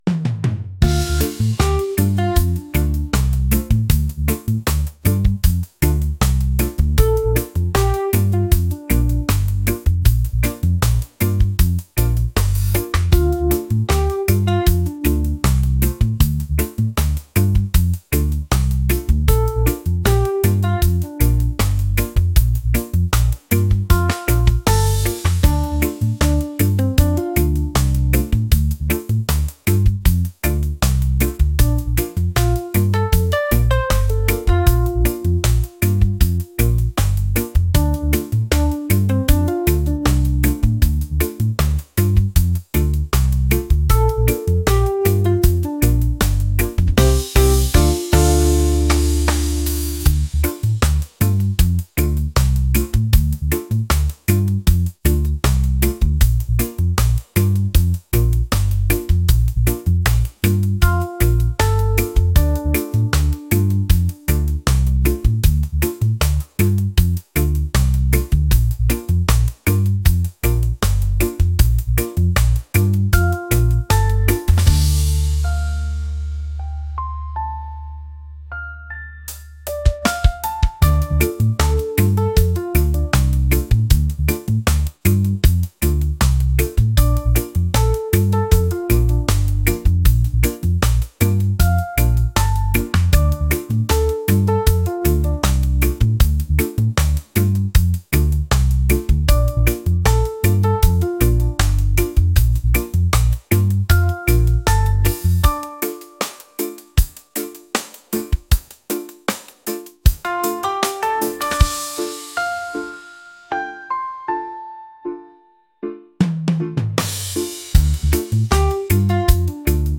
reggae | fusion | laid-back